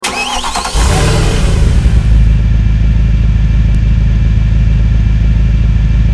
mere63_idle.wav